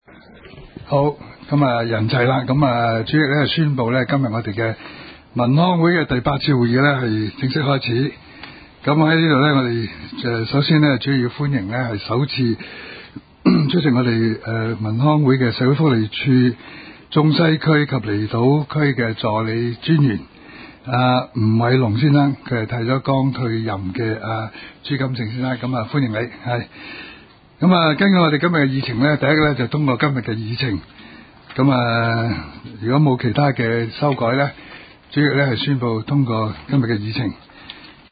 委员会会议的录音记录
中西区区议会会议室